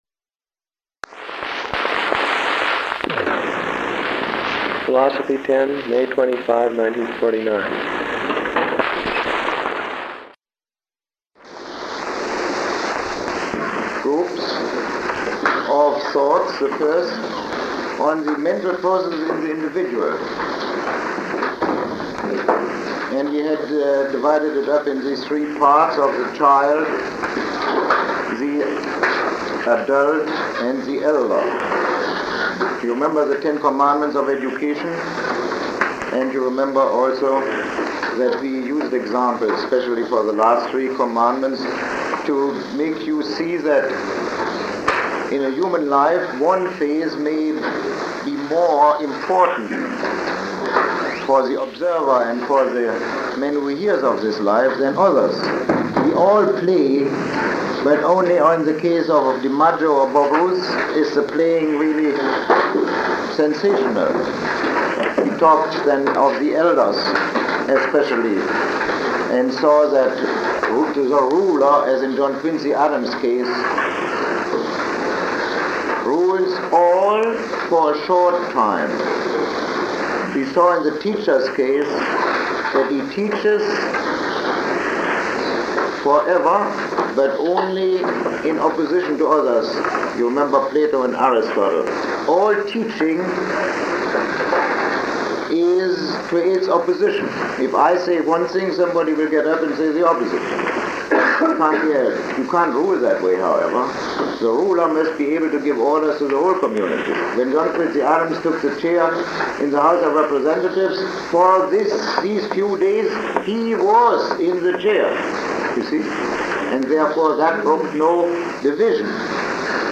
Lecture 6